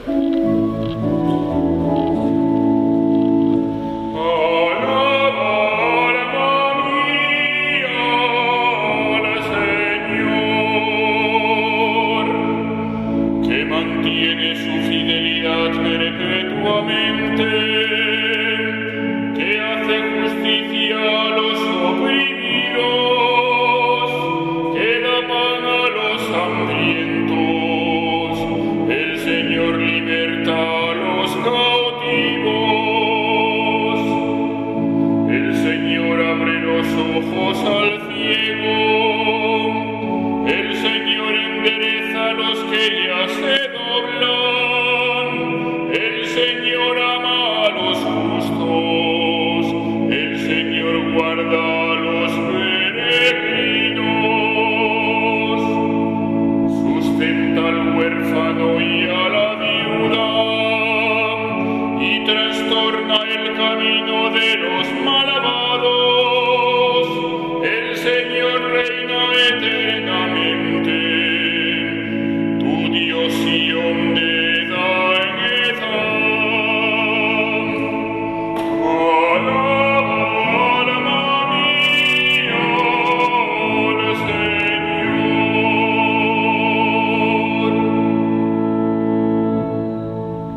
Salmo Responsorial [1.416 KB]